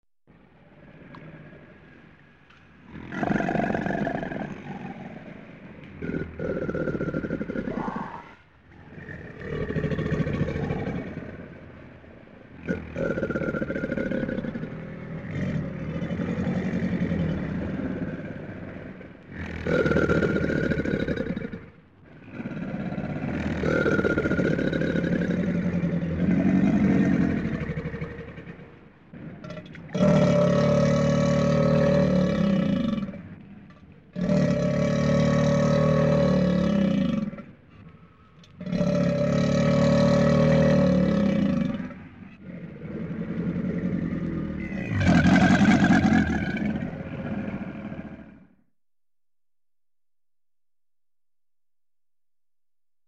Звуки крокодилов